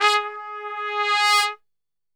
G#2 TRPSWL.wav